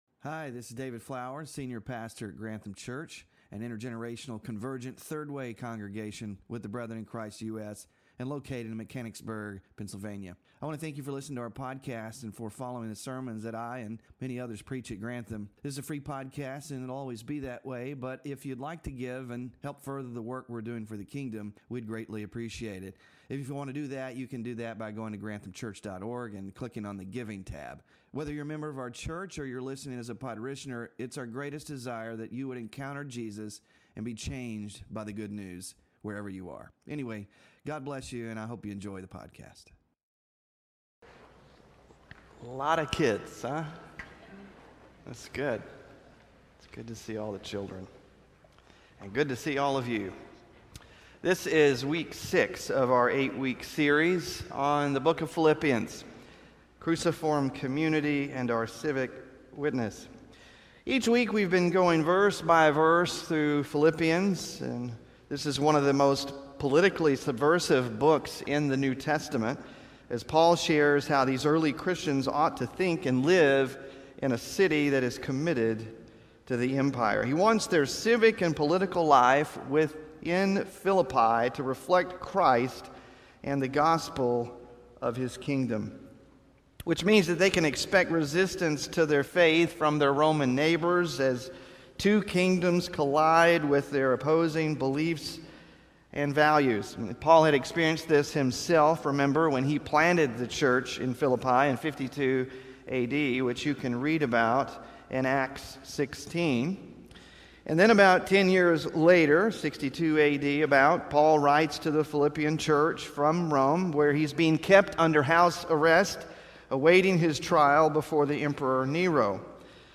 PHILIPPIANS SERMON SLIDES (6TH OF 8 IN SERIES) SMALL GROUP DISCUSSION QUESTIONS (9-15-24) BULLETIN (9-15-24)